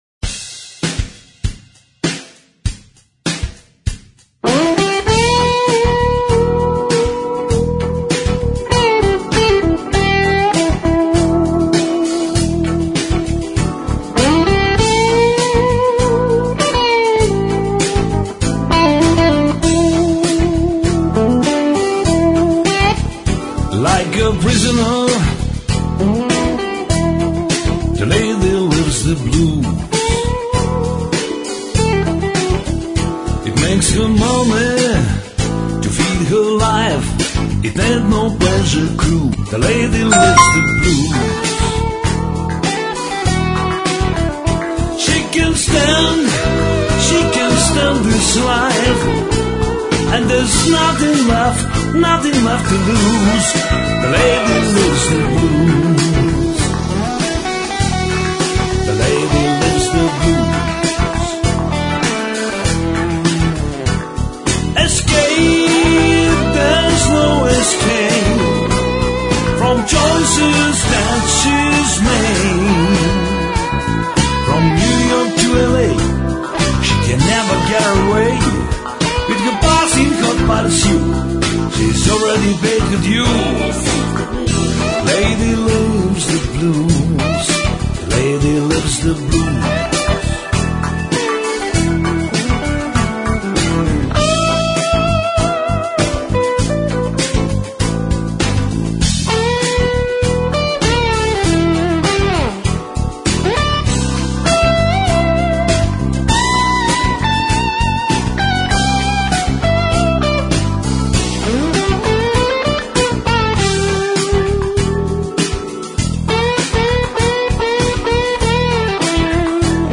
Blues Jazz